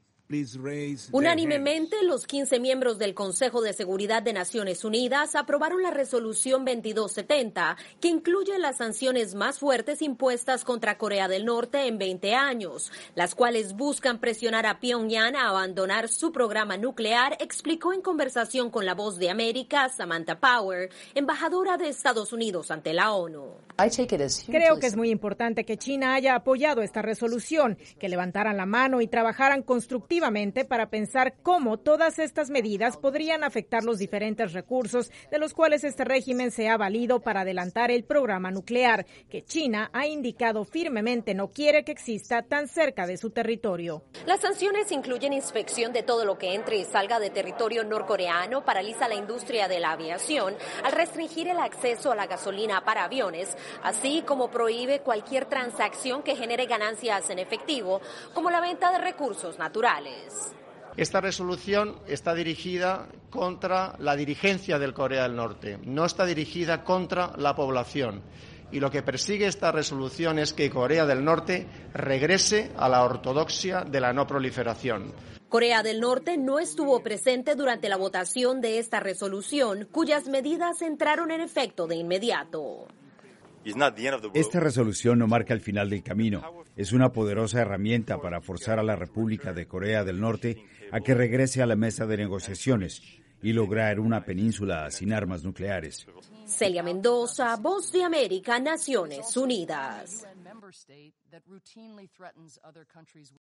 El Consejo de seguridad de la ONU aprobó una resolución que impone serias y fuertes sanciones contra el gobierno de la República de Corea del Norte. Informa desde la ONU en Nueva York